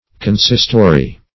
Consistory \Con*sis"to*ry\, a.